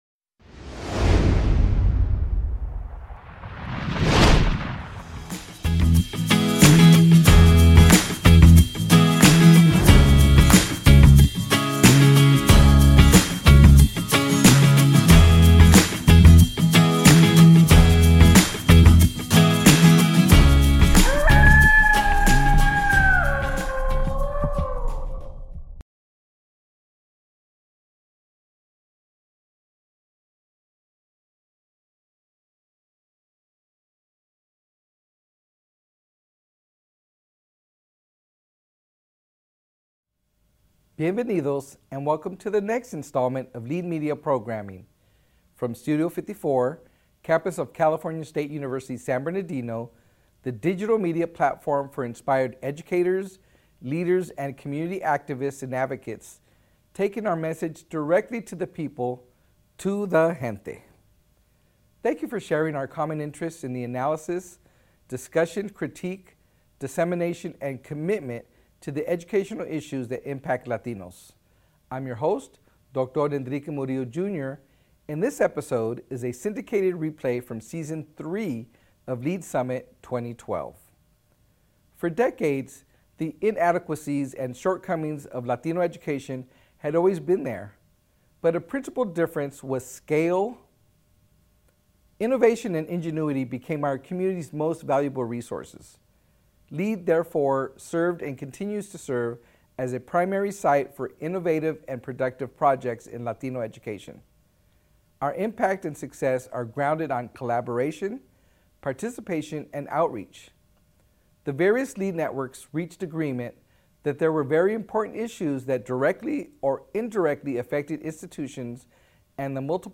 This panel brings together community college students from throughout the state of California who were participants of the Puente Project.